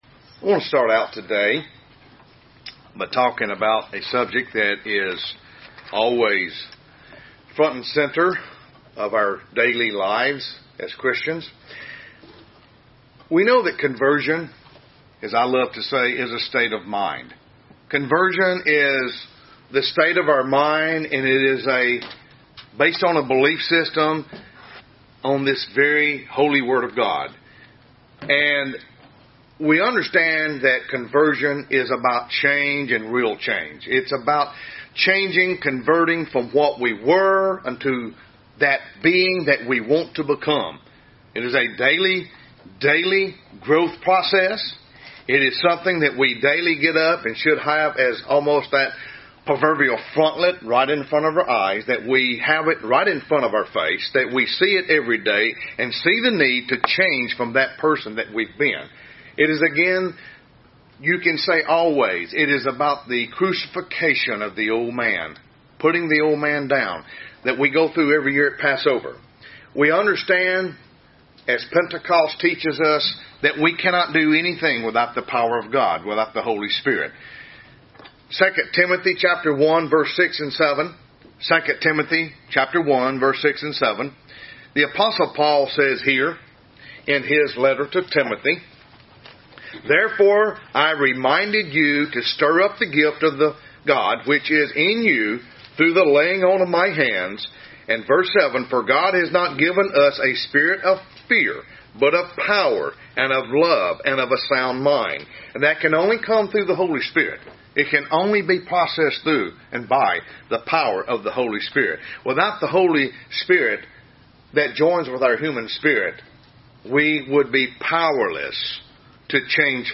Sermon
Given in Tupelo, MS